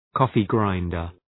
Shkrimi fonetik{‘kɔ:fı,graındər}